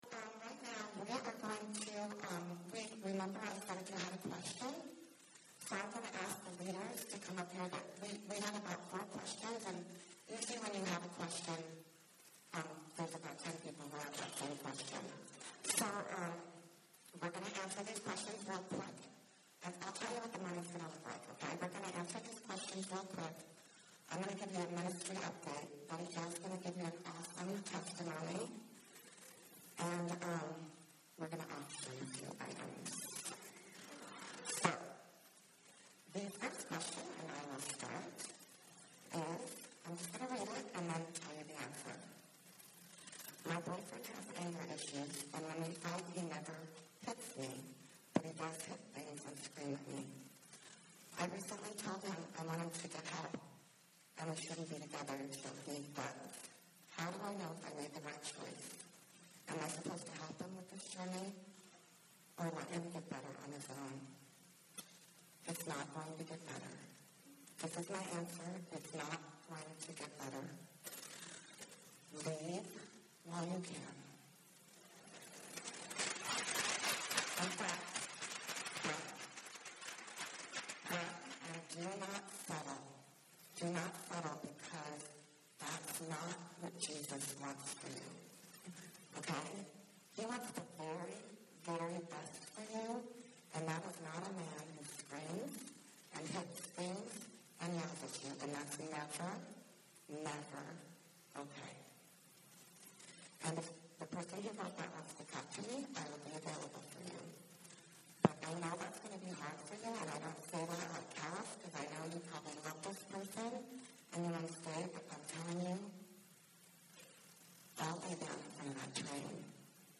2019 Women's Retreat: Q&A Ruth Selected Passages · Oct 20, 2019 · Multiple Listen as all of the speakers at Calvary Tucson's 2019 Women's Retreat: Ruth, A True Story, hold a Q&A session at the conclusion of the retreat.